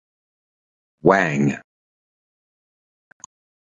Koncepcje Wang Tłumaczenia Wang Wang Wang Wang Wang Częstotliwość C1 Wymawiane jako (IPA) /wæŋ/ Etymologia (Angielski) Pokrewny z rosyjski Венгрия In summary From Chinese 王 (Wáng) or 汪 (Wāng).